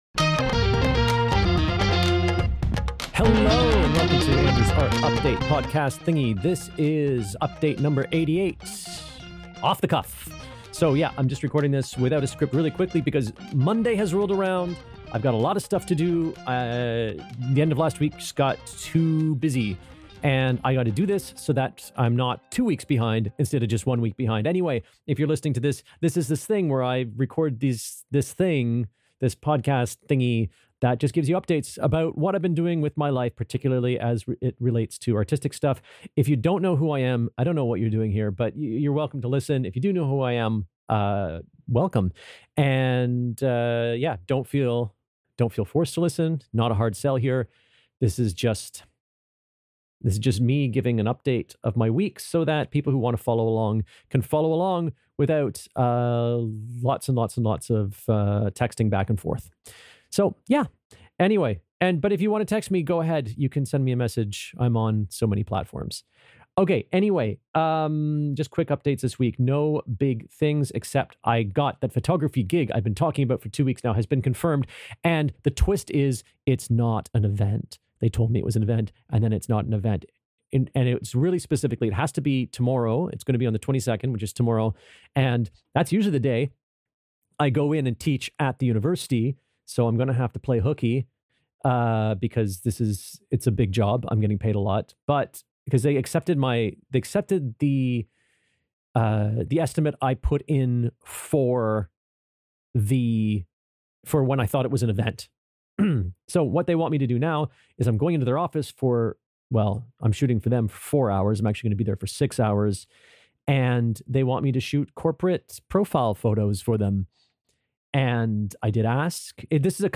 Unscripted and recorded in a rush, I mostly talk about an upcoming photography gig where the info I have comes late and is woefully incomplete. I'll follow up in a few days with the results.